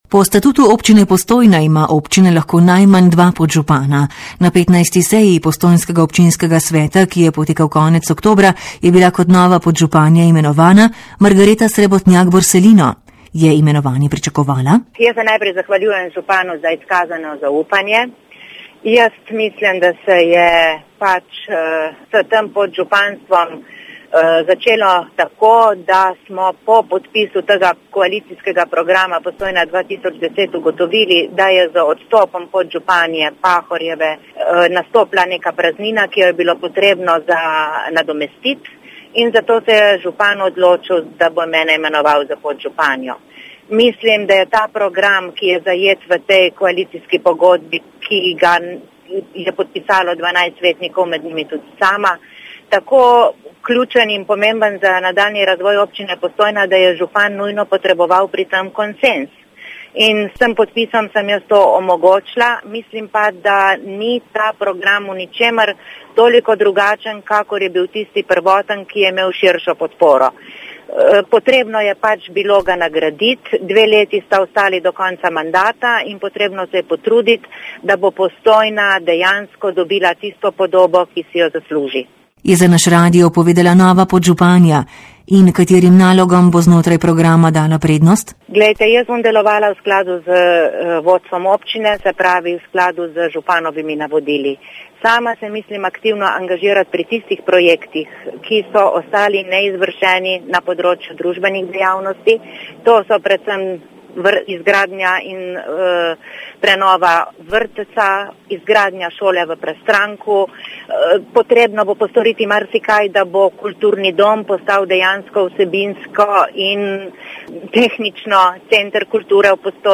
• novice radio94 r94
Katere naloge iz programa Postojna 2010 bi morale imeti prednost, smo vprašali novo postojnsko podžupanjo Margareto Srebotnjak Borsellino.